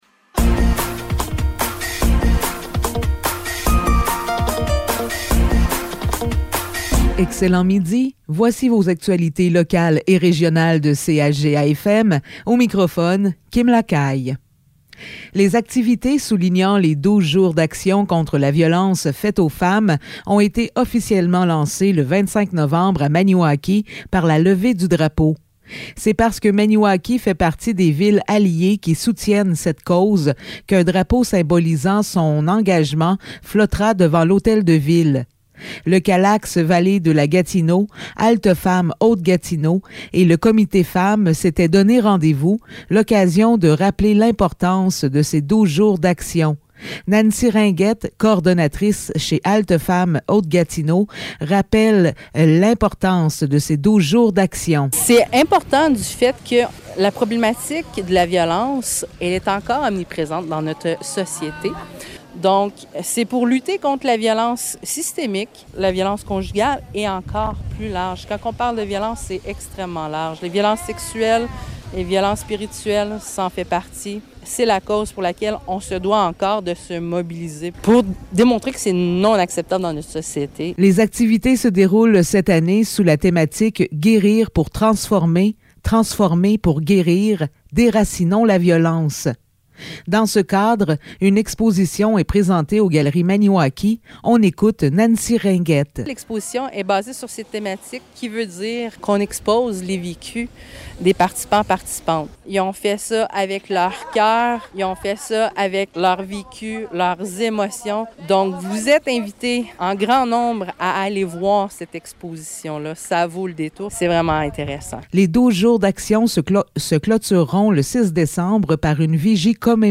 Nouvelles locales - 28 novembre 2022 - 12 h